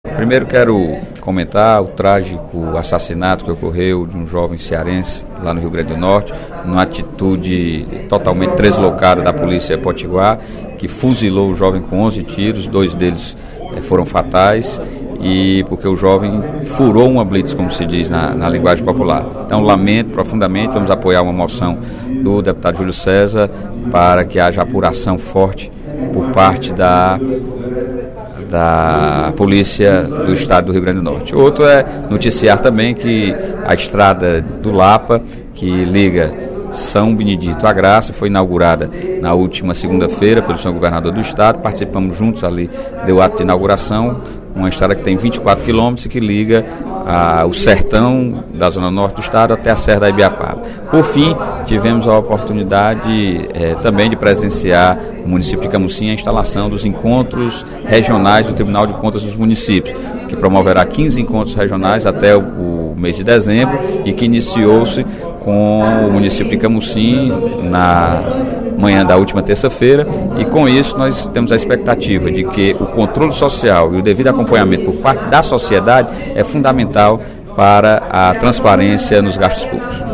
Durante o primeiro expediente desta quarta-feira (17/04), o deputado Sérgio Aguiar (PSB) destacou as inaugurações de escritórios da Ematerce no interior do Ceará, a construção da Ladeira da Lapa entre São Benedito e Graça, e informou que encaminhou pedido para que o Governo do Estado faça a revitalização da estrada entre Mucambo e Ibiapina, que irá facilitar o escoamento da produção local.
Sérgio Aguiar finalizou sua fala na tribuna comentando uma iniciativa do Tribunal de Contas dos Municípios (TCM), que fará 15 encontros regionais para orientar os gestores no controle social das contas públicas com o objetivo de facilitar o acesso da população a essas informações.